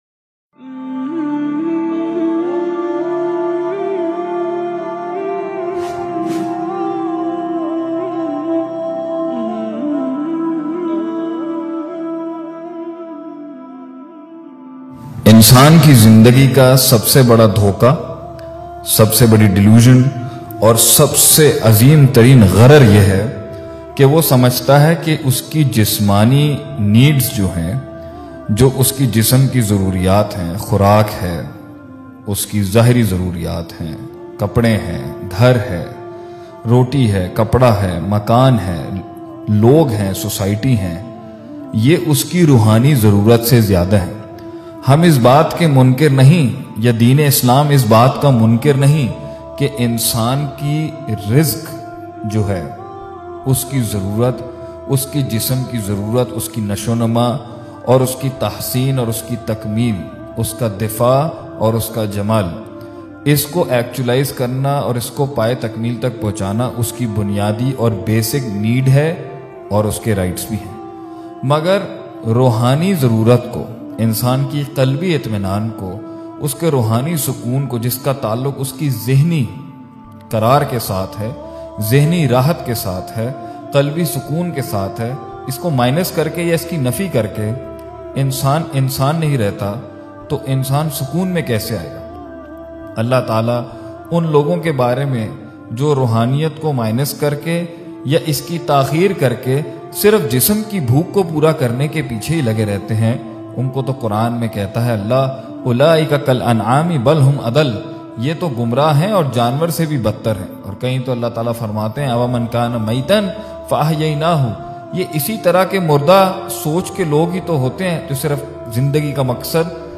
Insaan ki Bunyadi Zaroorat Motivational Speech MP3 Download